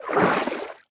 Sfx_creature_babypenguin_swim_barrel_roll_01.ogg